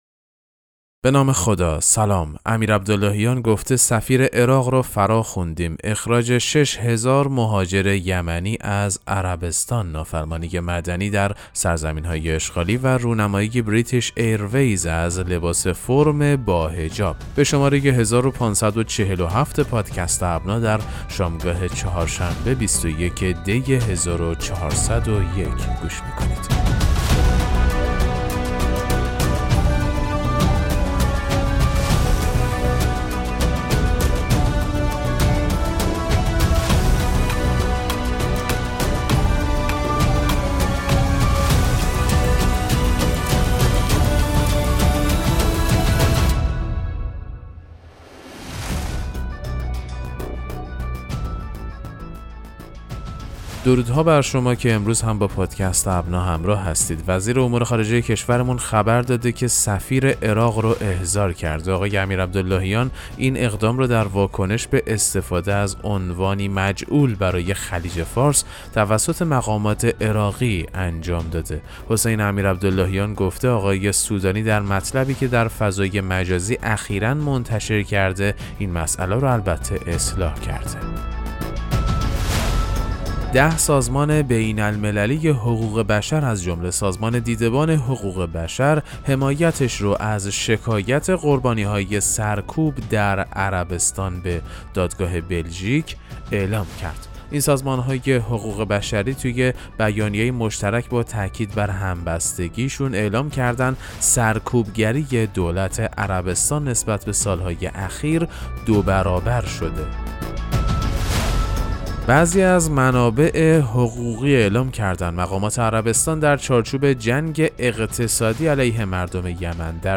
پادکست مهم‌ترین اخبار ابنا فارسی ــ 21 دی 1401